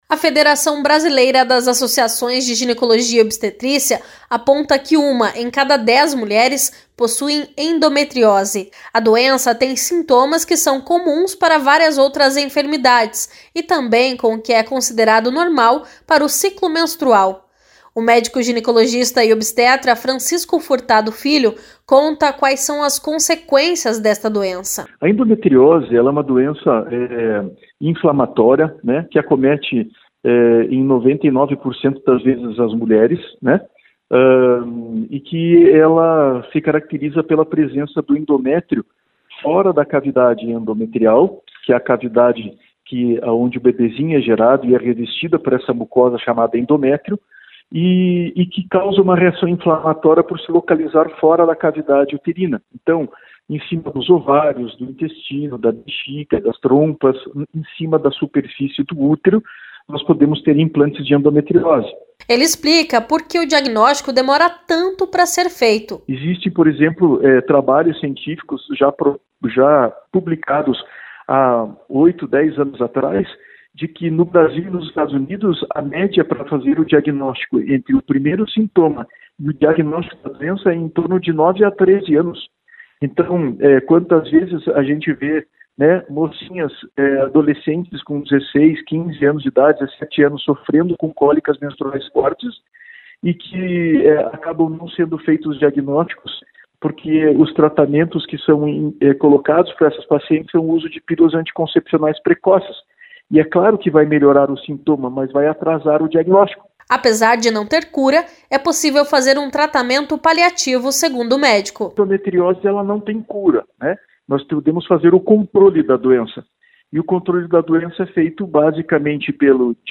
Ele explica porque o diagnóstico demora tanto para ser feito.